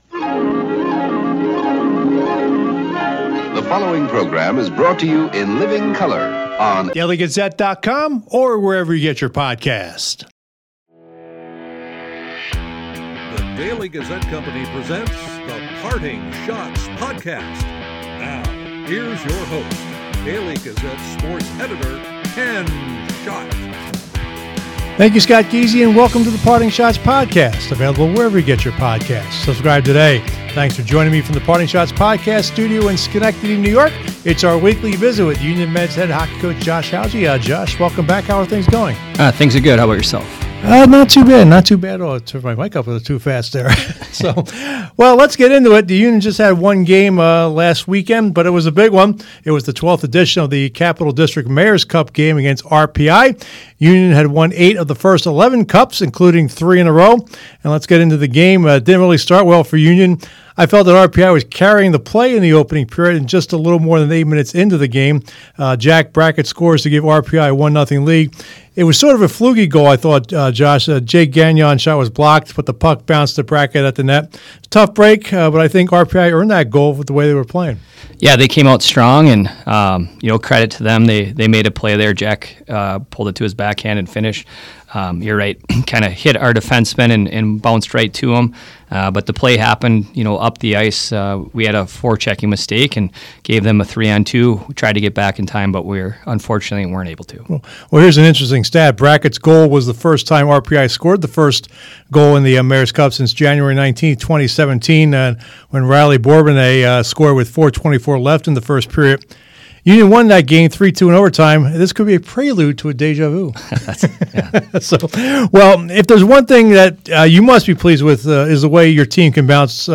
player interviews